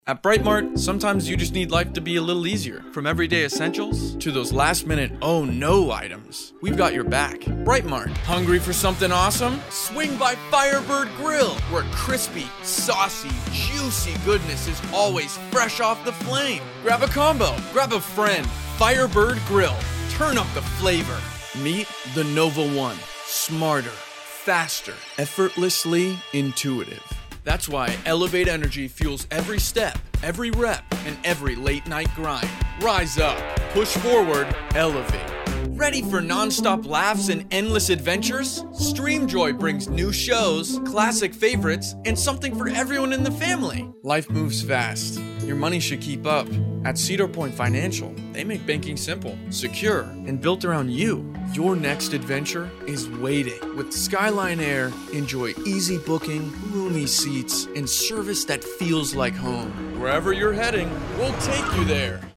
Actor and Voice Actor
Commercial Demo